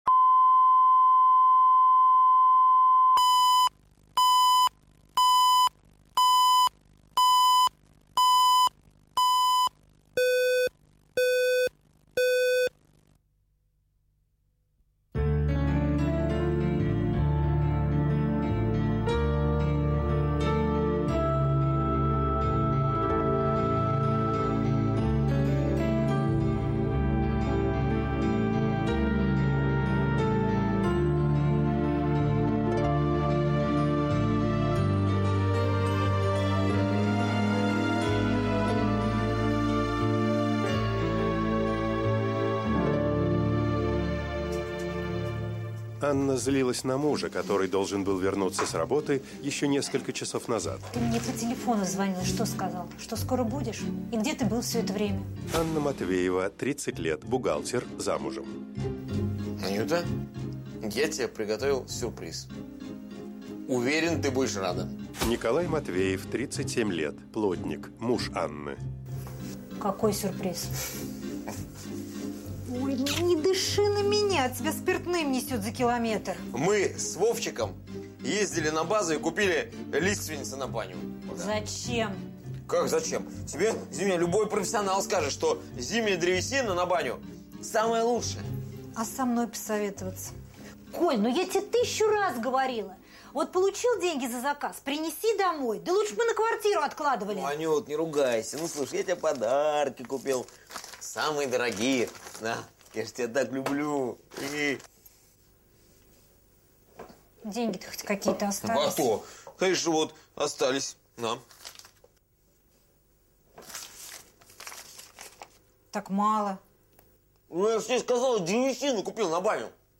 Аудиокнига Семейный бюджет | Библиотека аудиокниг
Прослушать и бесплатно скачать фрагмент аудиокниги